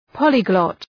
{‘pɒlı,glɒt}